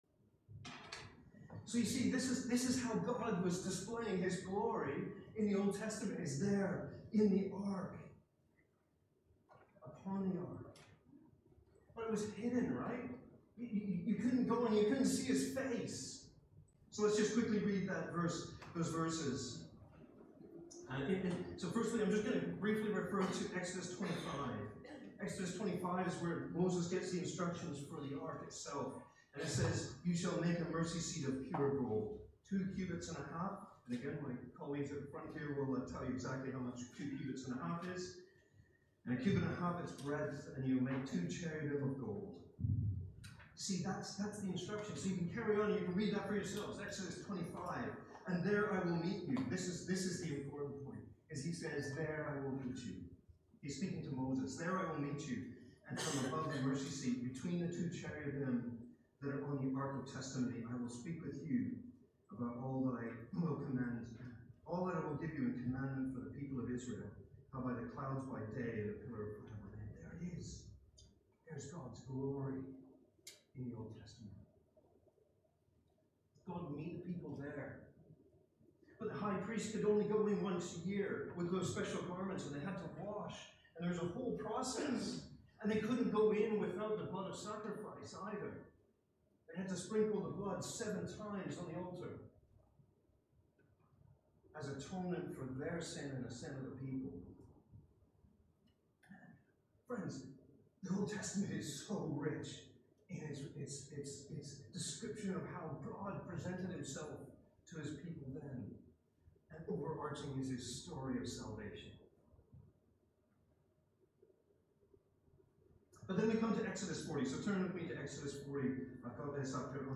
Sermons and Talks - Welcome Church